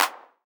ENE Clap.wav